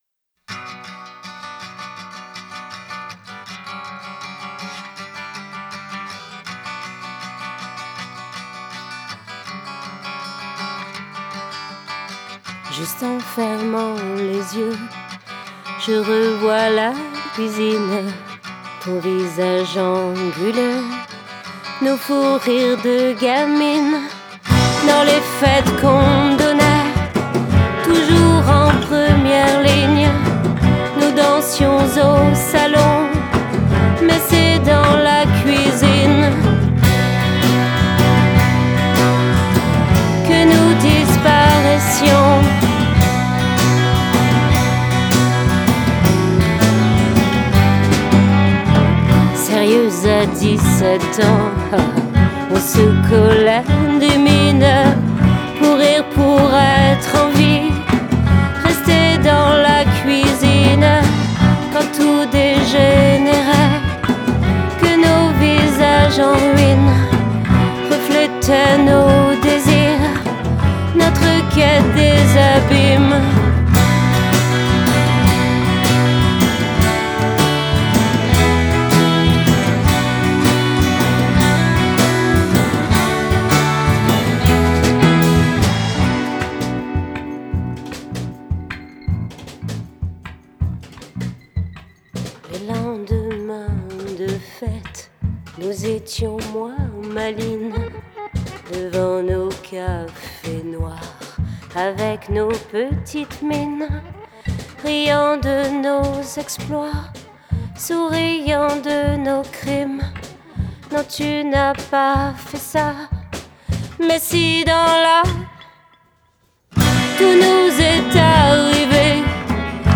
guitares/chant
batterie
basse
Enregistré rue de la Fidélité, Paris 10,